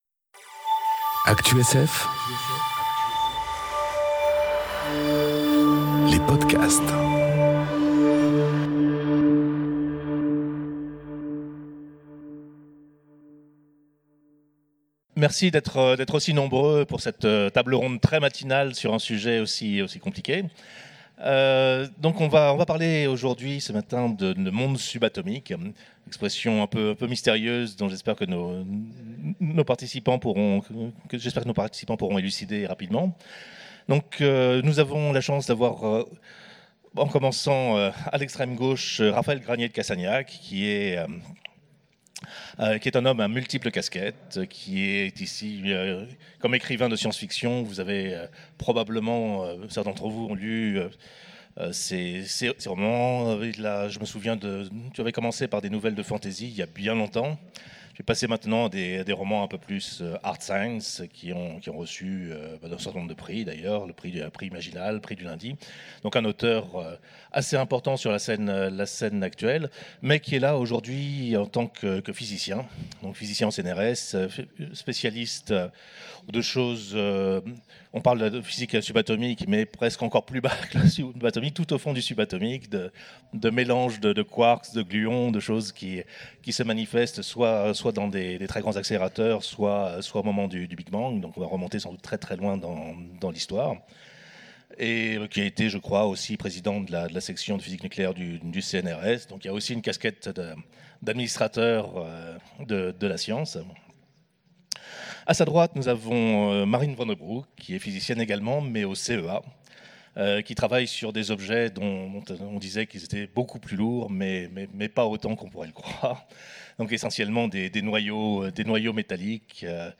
Conférence Le monde subatomique enregistrée aux Utopiales 2018